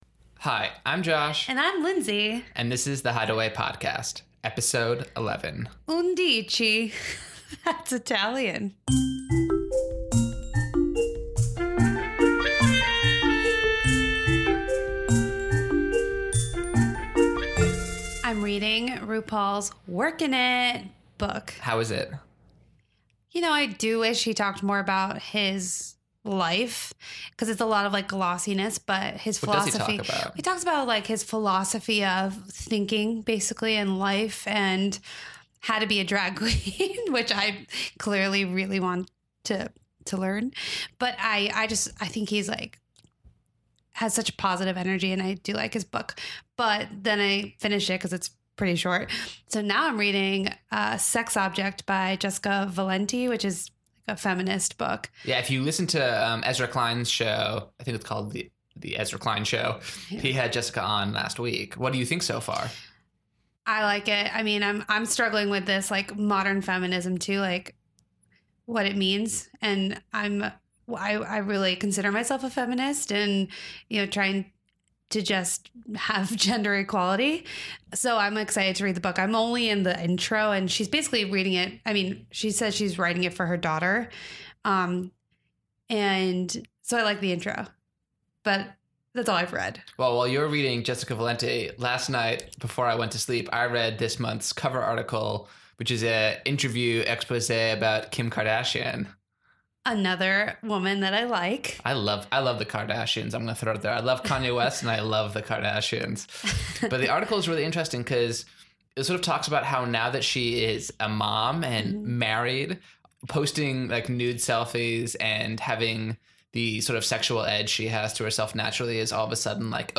Swedish circus artist